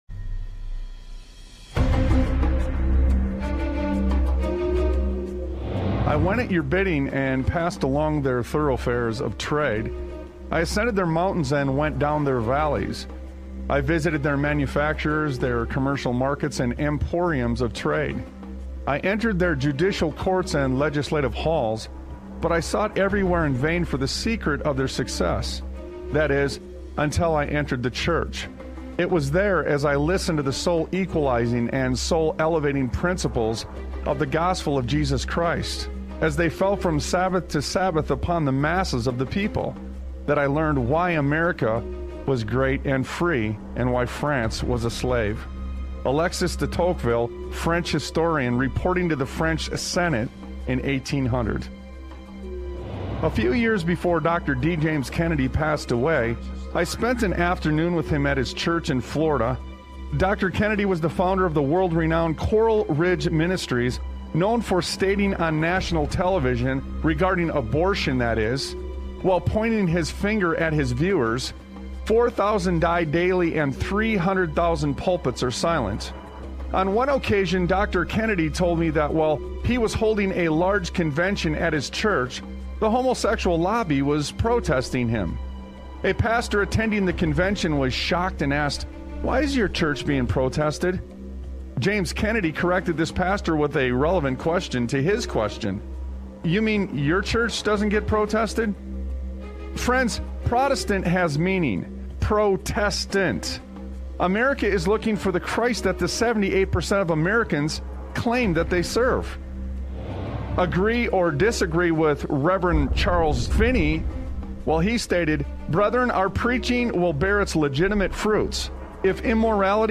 Talk Show Episode, Audio Podcast, Sons of Liberty Radio and The Tracks That Should Be Followed on , show guests , about The Tracks That Should Be Followed, categorized as Education,History,Military,News,Politics & Government,Religion,Christianity,Society and Culture,Theory & Conspiracy